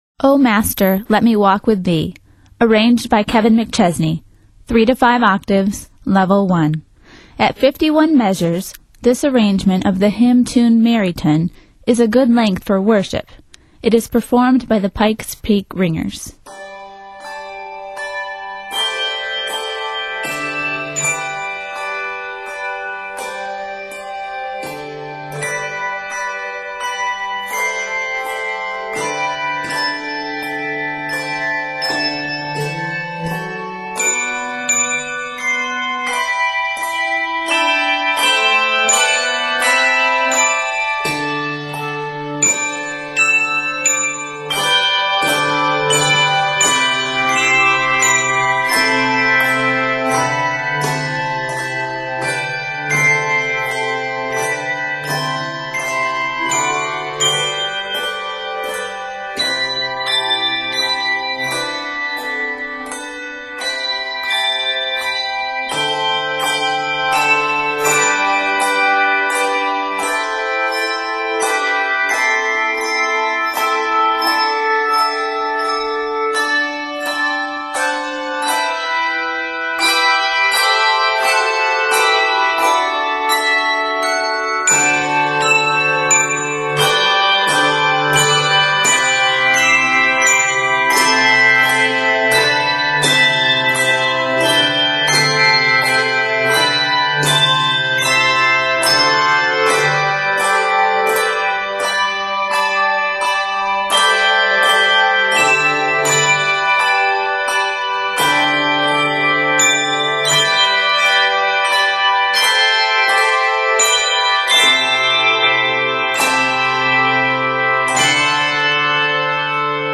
Set for 3, 4 or 5 octaves of handbells